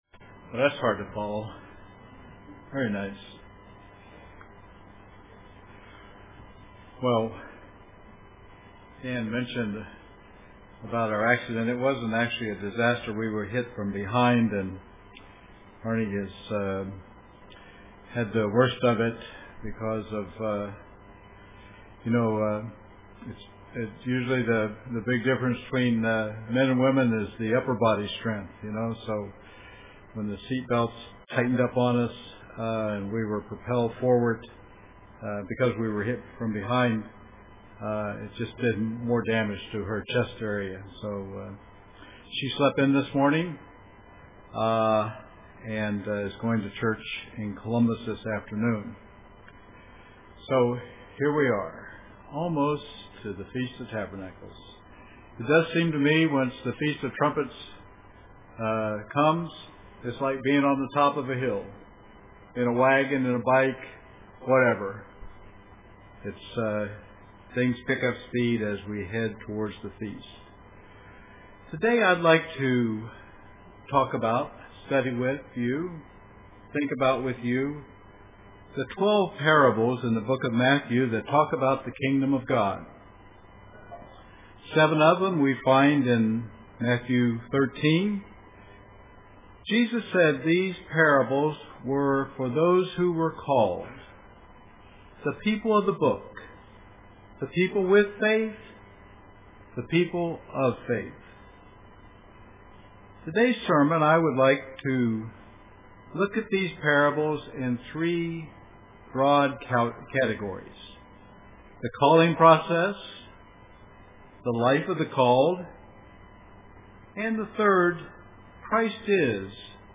Print An examination of Christ's parables in Matthew 13.The Parables of the Kingdom UCG Sermon Studying the bible?